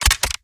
GUNMech_Rocket Launcher Reload_07_SFRMS_SCIWPNS.wav